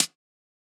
UHH_ElectroHatD_Hit-15.wav